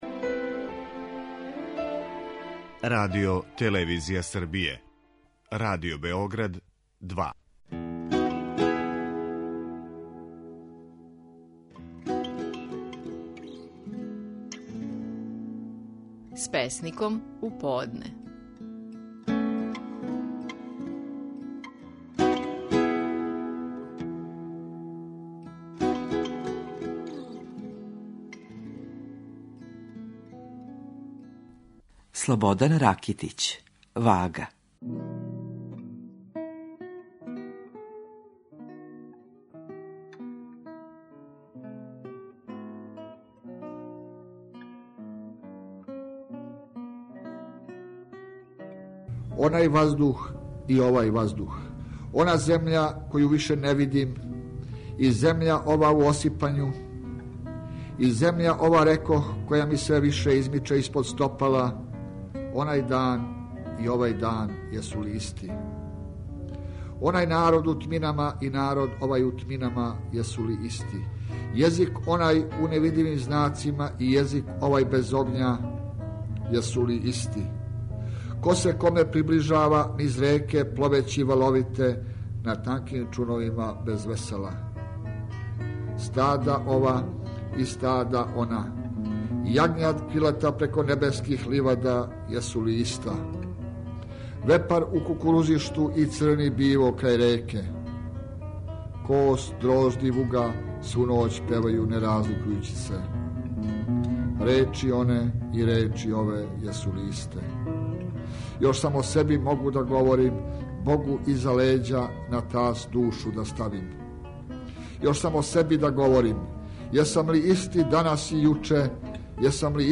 Стихови наших најпознатијих песника, у интерпретацији аутора.
Слободан Ракитић говори своју песму "Вага".